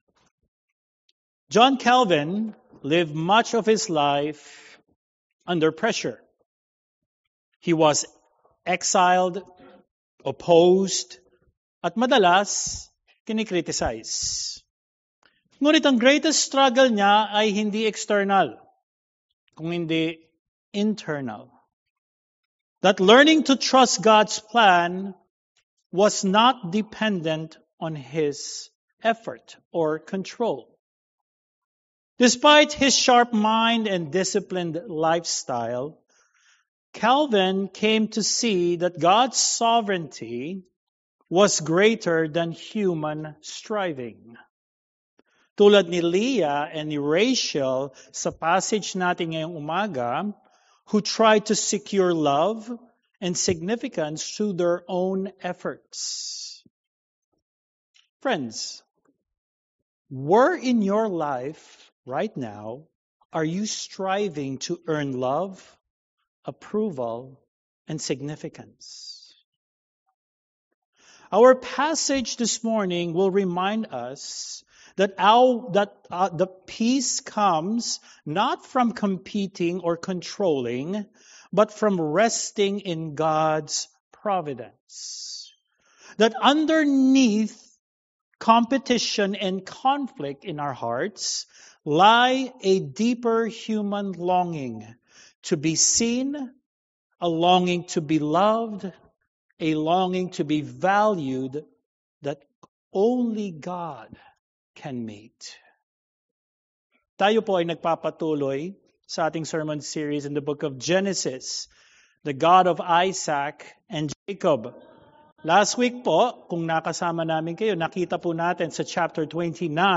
Continuation of the sermon series in the book of Genesis